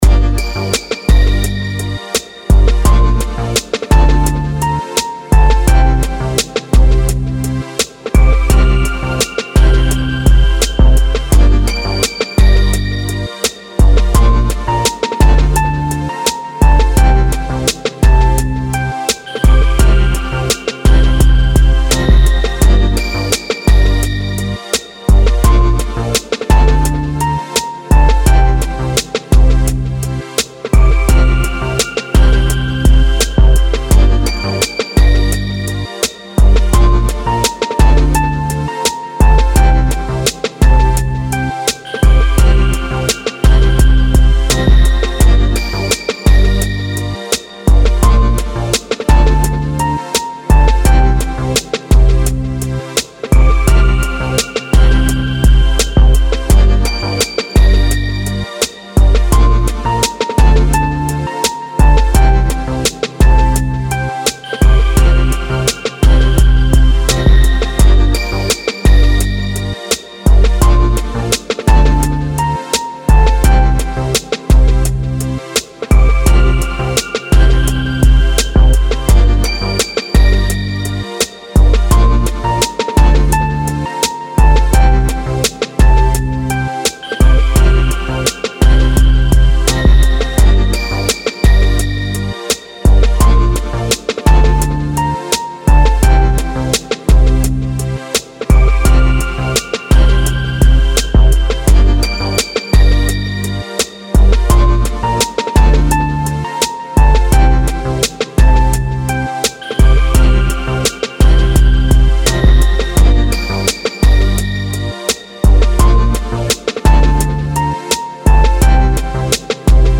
音楽ジャンル： ヒップホップ
楽曲の曲調： SOFT
雨で一休みしている感じのBGM等に。